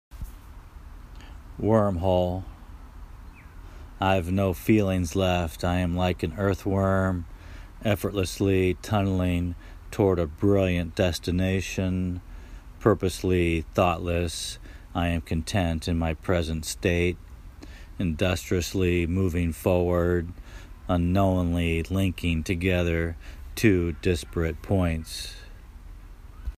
Nice reading friend